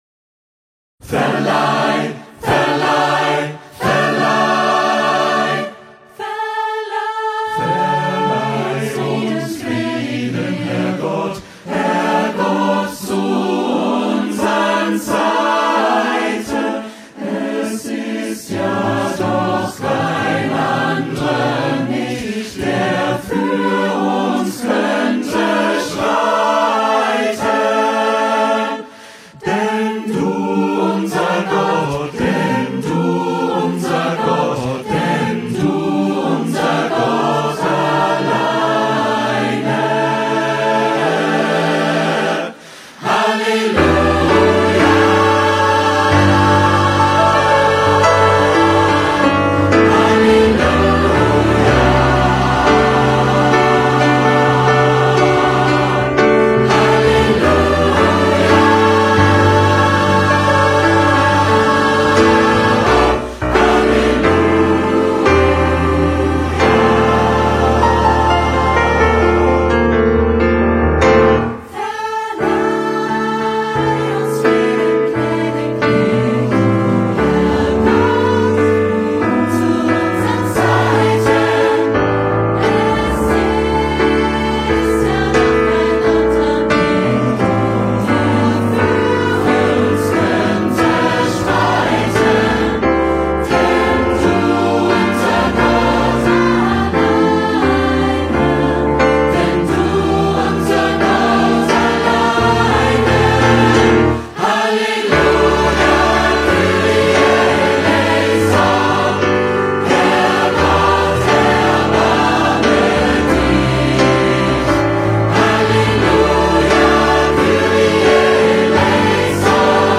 Besetzung: Gemischter Chor (S.A.T.B.), Klavier